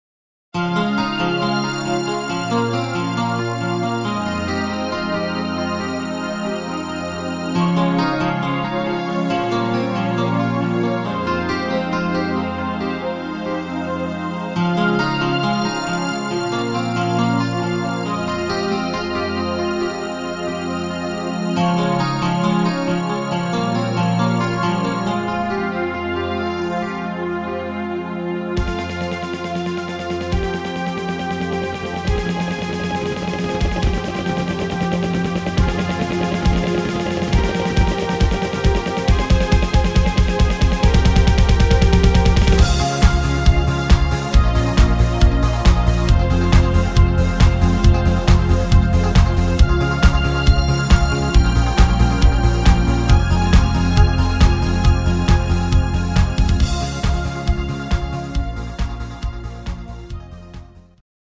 instr. Synthesizer